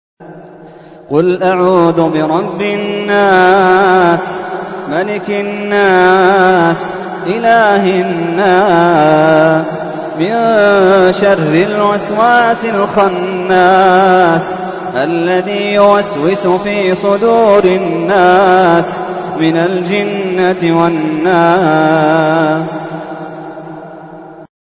تحميل : 114. سورة الناس / القارئ محمد اللحيدان / القرآن الكريم / موقع يا حسين